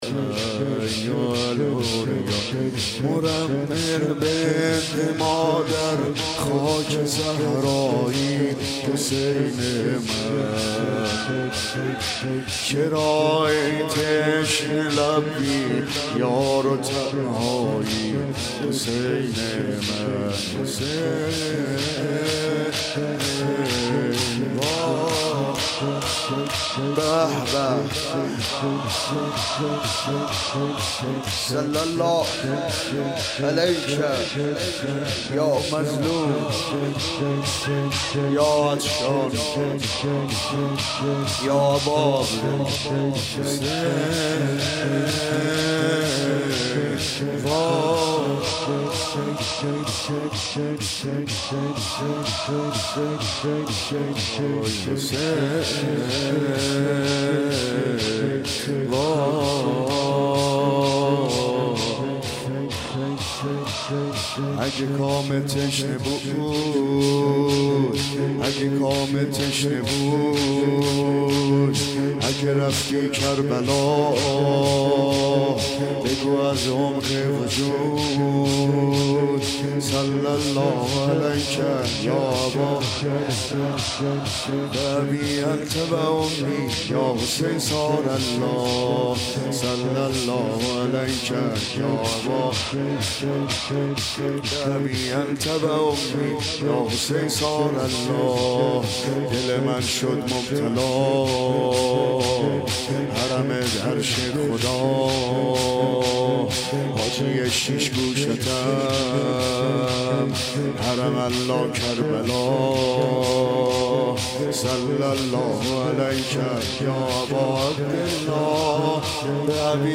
روضه ، مناجات ، سینه زنی زمینه
سینه زنی شور ، روضه ، صحبت